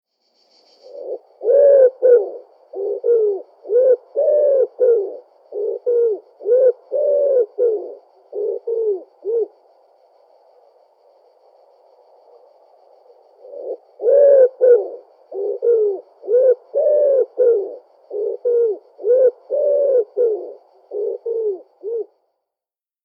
Ringeltaube
Hören Sie sich hier das Gurren der Ringeltaube an Ringeltaube Artenbeschreibung: Basisinformation Mehr lesen zum Thema:
Ringeltaube_AMPLE_E07571.mp3